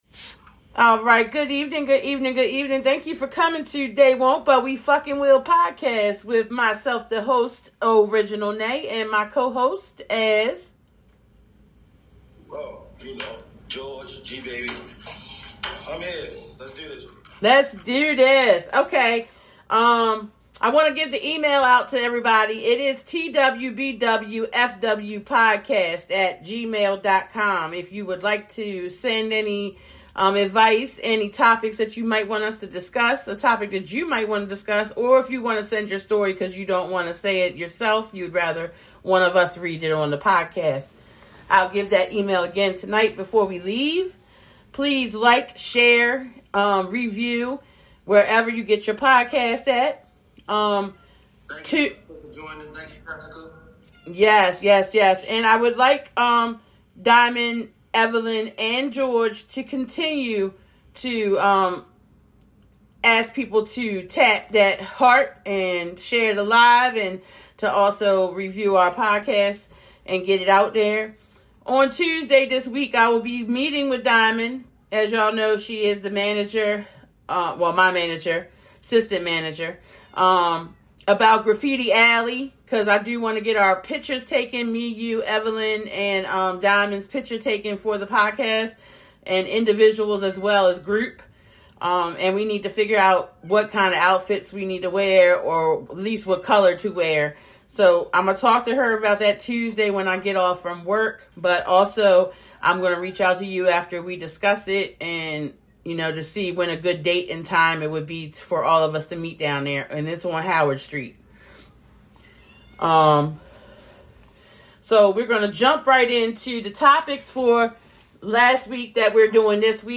This week we host and co host went out of town but we still came together to record.